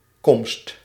Ääntäminen
IPA : /əˈɹaɪ.vəl/